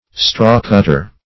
Straw-cutter \Straw"-cut`ter\, n. An instrument to cut straw for fodder.